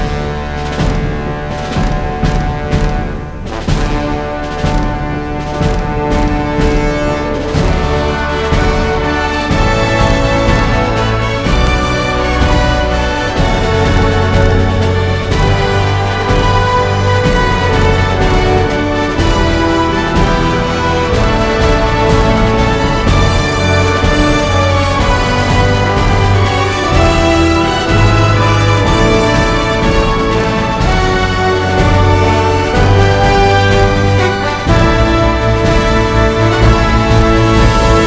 The music from the show is an Irish delight.